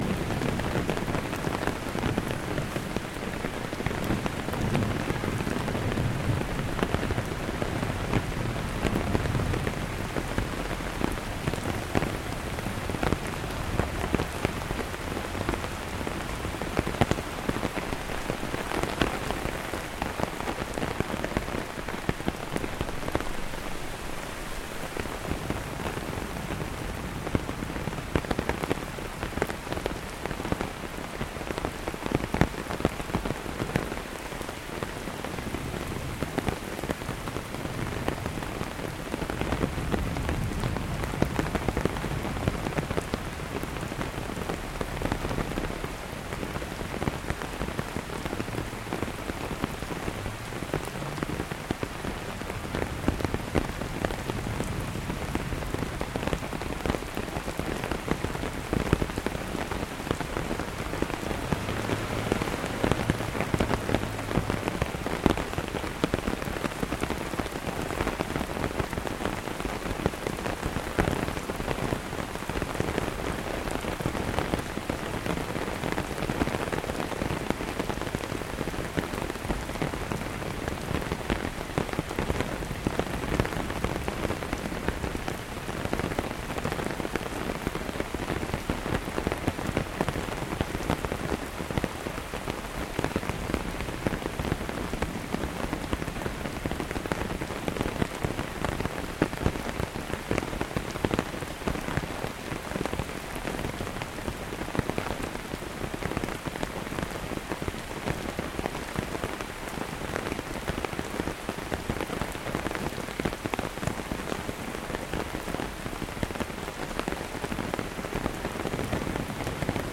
Здесь вы найдете подборку умиротворяющих звуков: от шелеста листвы до мерного гудения кофейни.
Шум дождевых капель, стучащих по зонту на улице